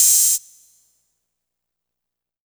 openhat wondagurl.wav